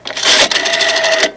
Dialing A Phone Sound Effect
Download a high-quality dialing a phone sound effect.
dialing-a-phone-1.mp3